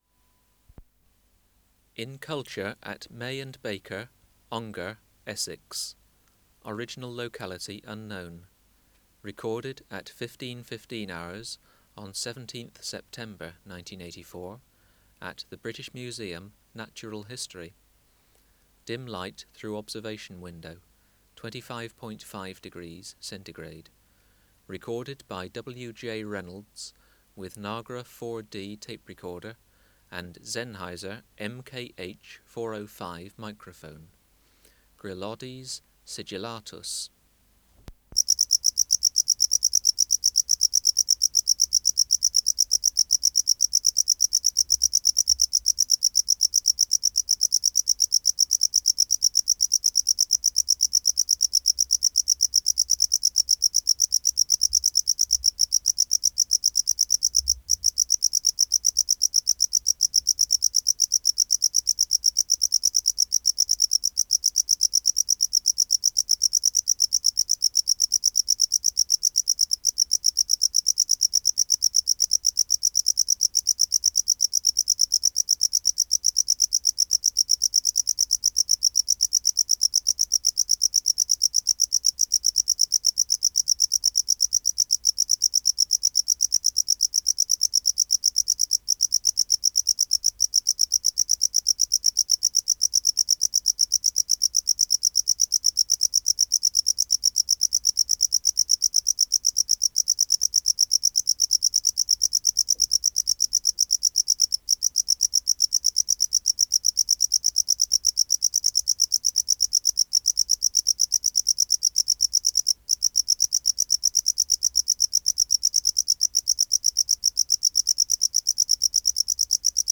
417:3 Gryllodes sigillatus(550a) | BioAcoustica: Wildlife Sounds Database
Recording Location: BMNH Acoustic Laboratory
Reference Signal: 1 kHz for 10 s
Substrate/Cage: Small recording cage
Microphone & Power Supply: Sennheiser MKH 405 Distance from Subject (cm): 18 Filter: Low pass, 24 dB per octave, corner frequency 20 kHz